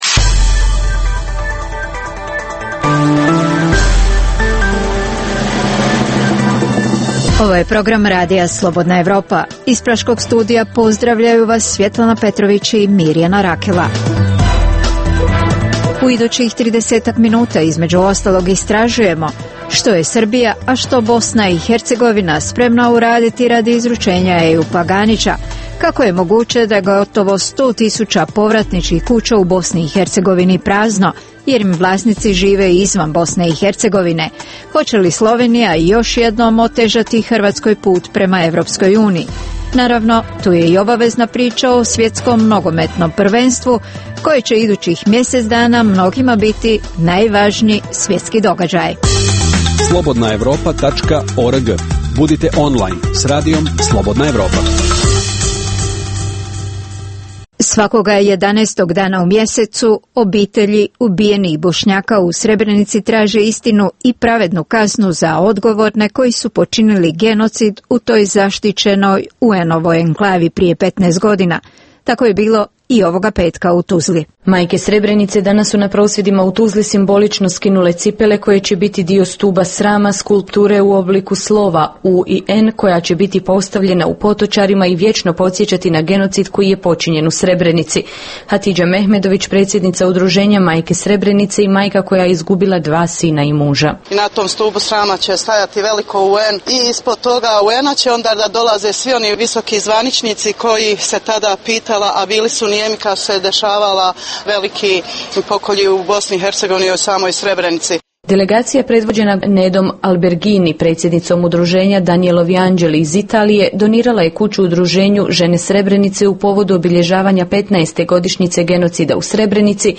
U regionalnom istražujemo što je Srbija sve spremna uraditi radi izručenja Ejupa Ganića, da li je Hrvatska pred novom slovenskom blokadom za ulazak u EU, govorimo o Svjetskom nogometnom prvenstvu koje će idućih mjesec dana mnogima biti najvažniji događaj. Uz ove teme objavljujemo i razgovor sa direktorom washingtonskog Instituta za mir Danelom Serwerom.